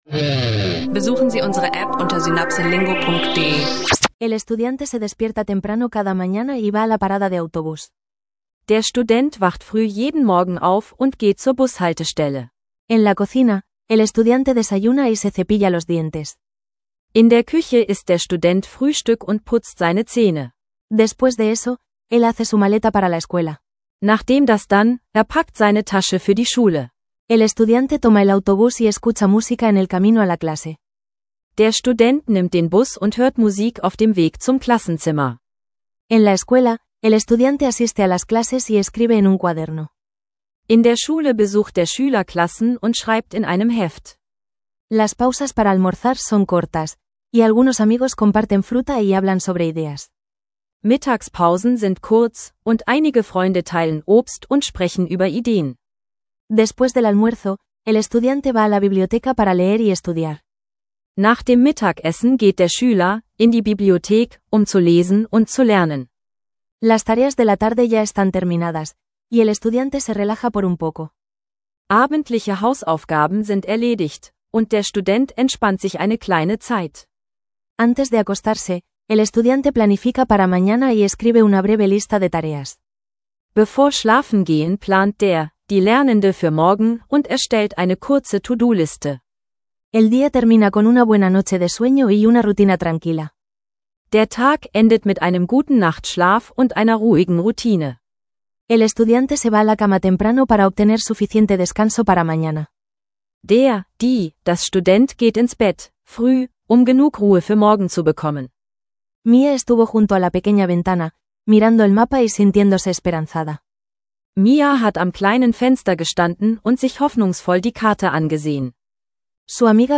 Lerne Alltagstätigkeiten und Reisetipps auf Spanisch – praxisnaher Spanisch Sprachkurs für Anfänger mit kurzen Dialogen und nützlichen Vokabeln.